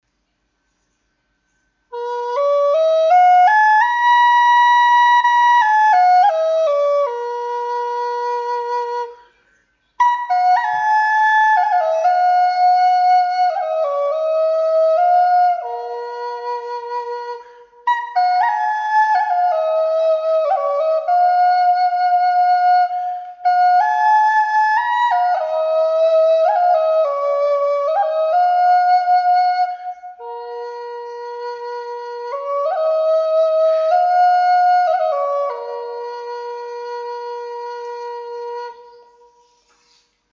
Western Cedar Native American Flutes
This is one of my favorite woods to make Native American Flutes out of because it is the most "mellow" sounding of all! 5 Hole Western Cedar Flues are also great Native American Flutes for beginners because the wood is so "forgiving" of the playing styles of new flute players.
$135 Key of High B